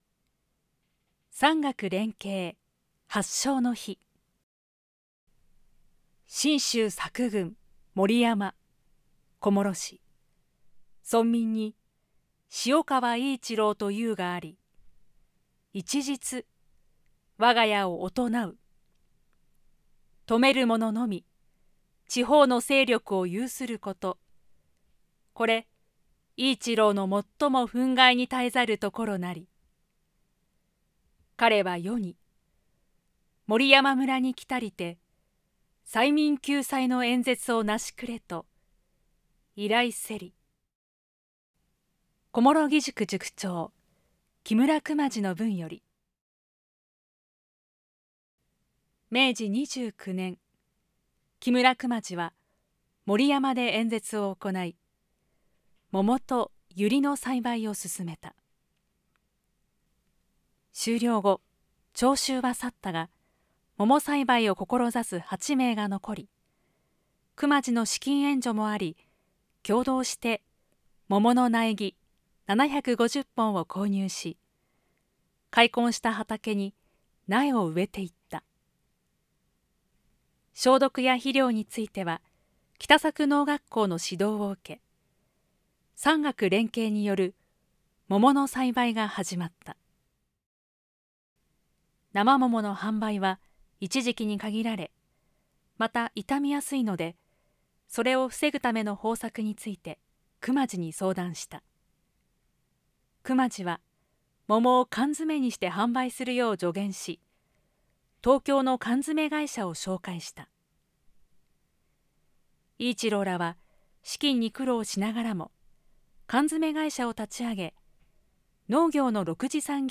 産学連携発祥の碑ナレーション（音声）